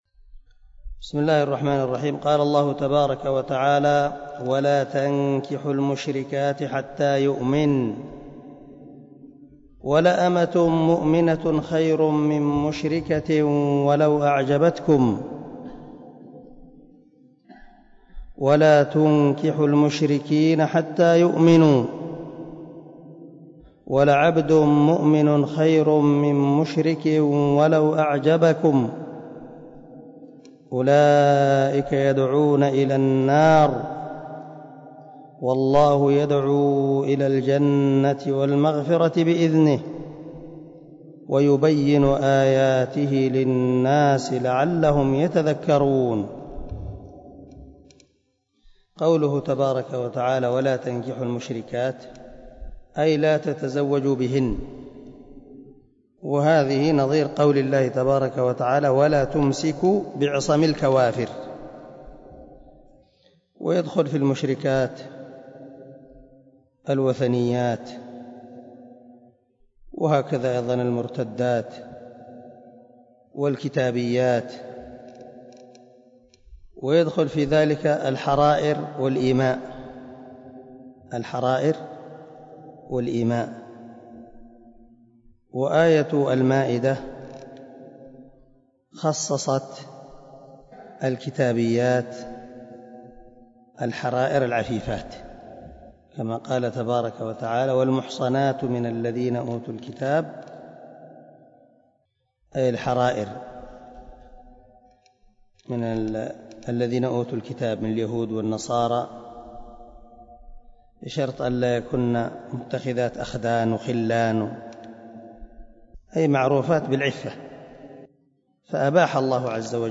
110الدرس 100 تفسير آية ( 221 ) من سورة البقرة من تفسير القران الكريم مع قراءة لتفسير السعدي